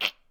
capri_jump3.ogg